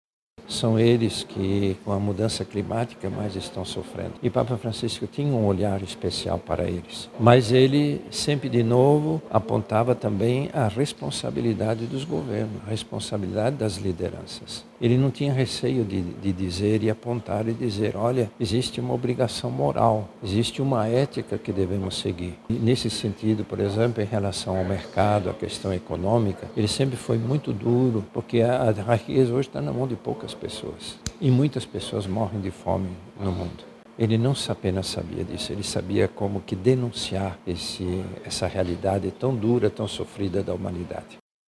O cardeal da Amazônia, Dom Leonardo Steiner, ressalta que o Papa além de mobilizar a Igreja em prol dos mais necessitados também cobrava dos governos políticas públicas voltadas as pessoas em situação de pobreza.
Sonora-Cardeal-ok-1.mp3